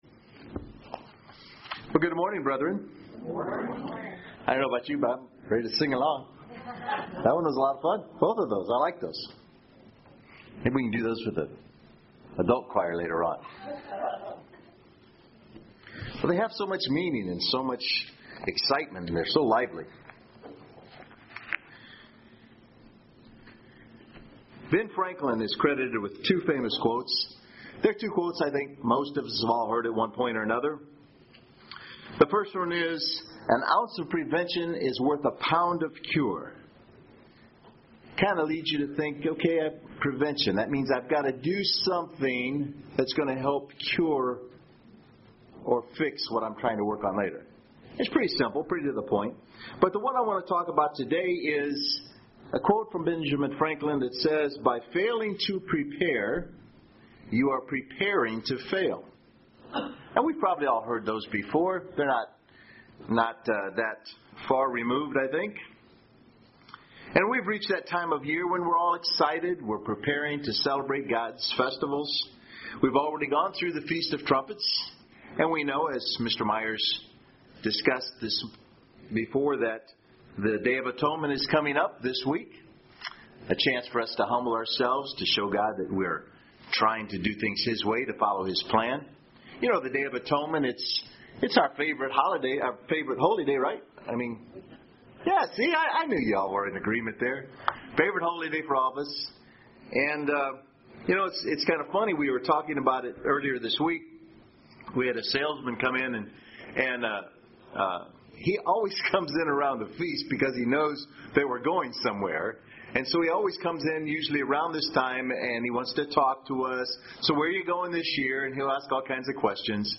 Given in Murfreesboro, TN
UCG Sermon Studying the bible?